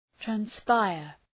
Προφορά
{træn’spaıər}